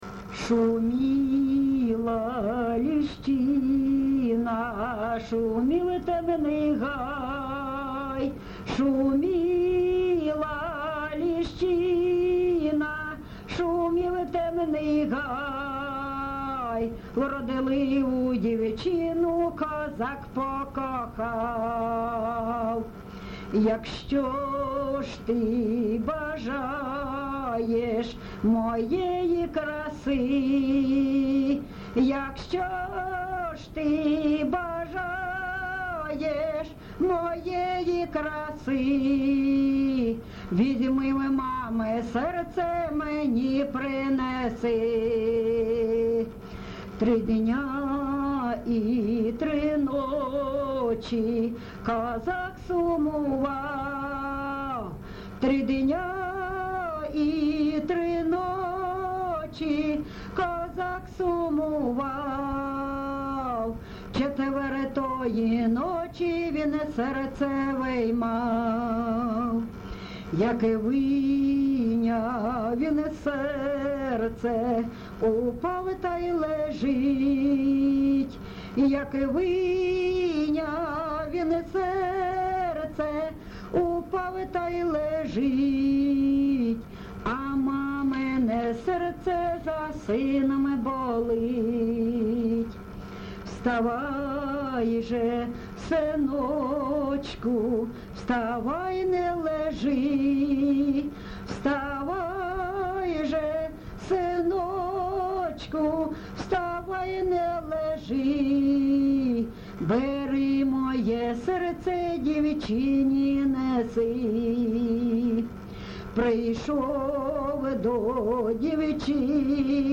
ЖанрПісні з особистого та родинного життя, Балади
Місце записус. Лозовівка, Старобільський район, Луганська обл., Україна, Слобожанщина